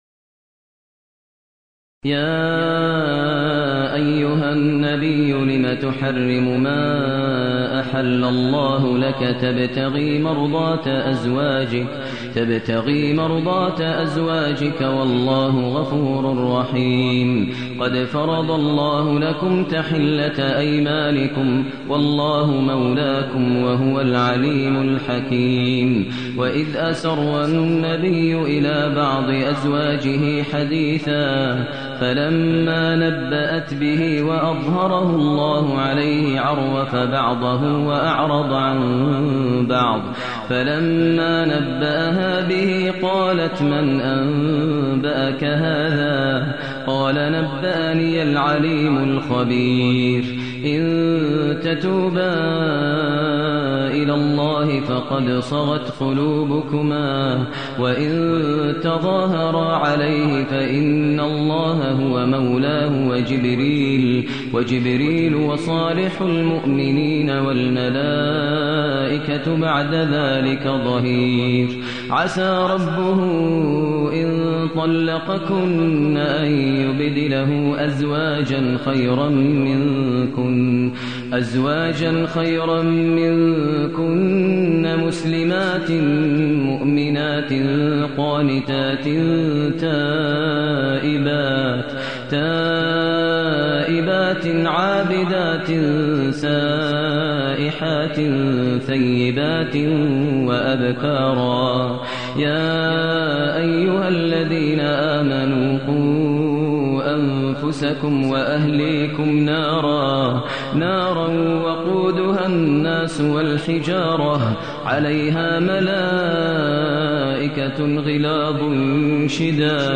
المكان: المسجد الحرام الشيخ: فضيلة الشيخ ماهر المعيقلي فضيلة الشيخ ماهر المعيقلي التحريم